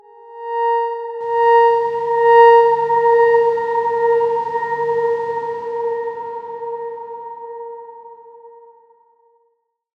X_Darkswarm-A#4-mf.wav